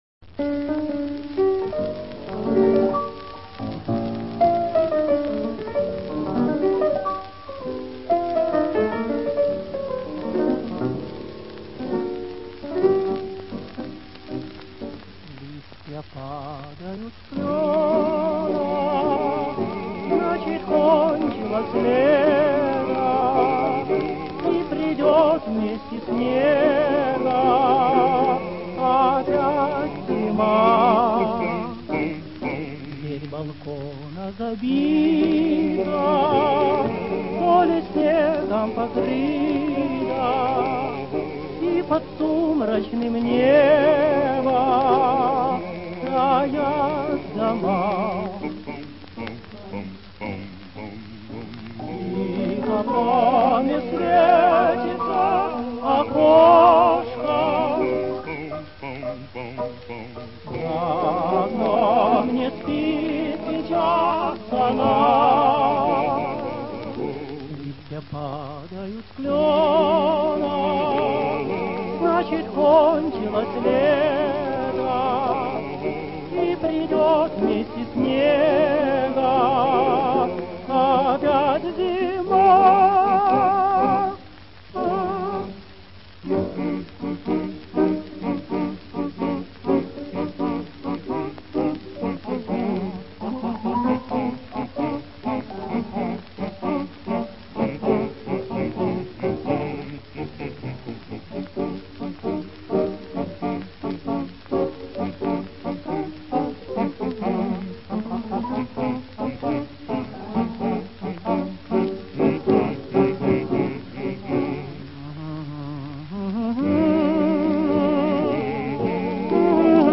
За фортепьяно